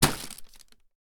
rustling hit effect 02
bonk effect fist hit impact rustling short thud sound effect free sound royalty free Sound Effects